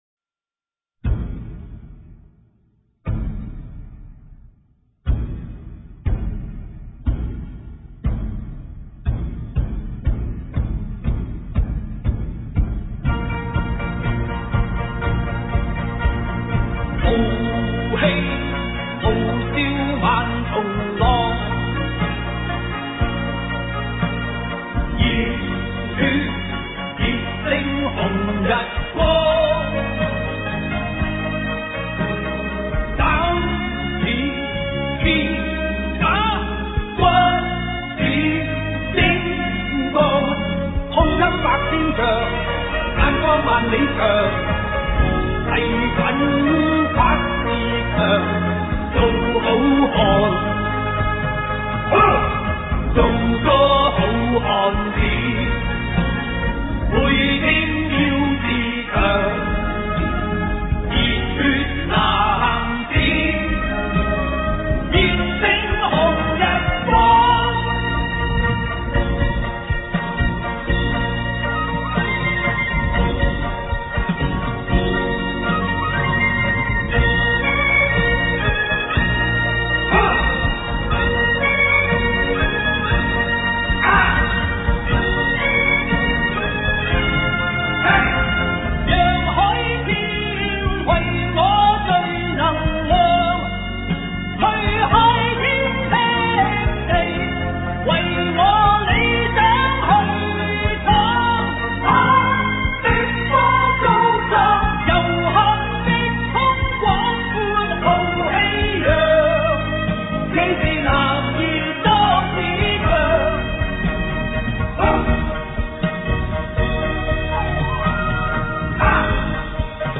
節奏铿锵高昂
他有動力強勁的「天生奇喉」，音域超闊。有一副極具衝擊力和爆破力的嗓音，歌喉特別「嗆」。